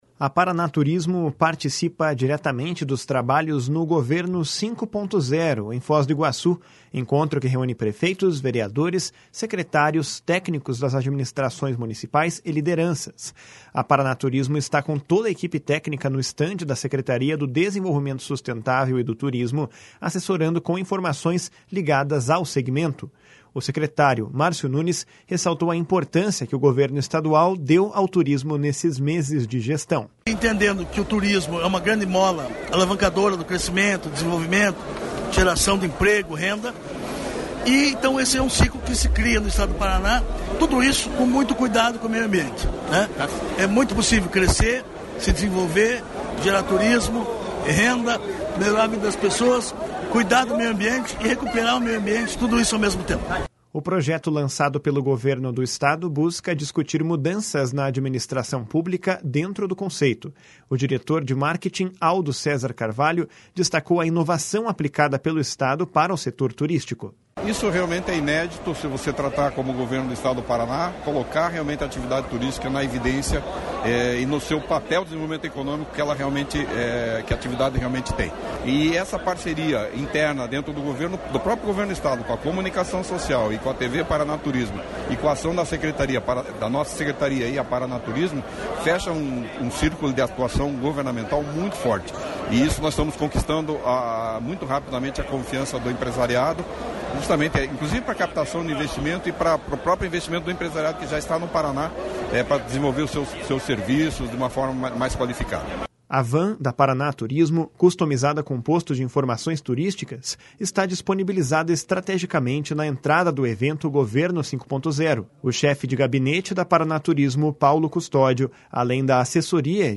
A Paraná Turismo está com toda equipe técnica no estande da Secretaria do Desenvolvimento Sustentável e do Turismo assessorando com informações ligadas ao segmento. O secretário Márcio Nunes ressaltou a importância que o governo estadual deu ao turismo nesses meses de gestão.